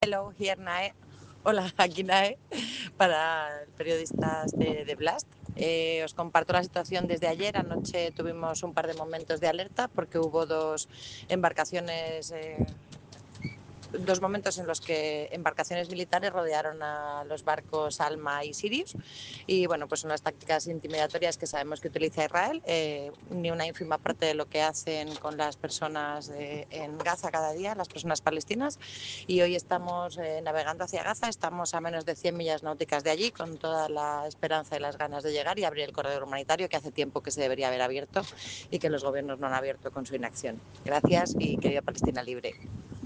Barco Jeannot III. 1 de octubre de 2025.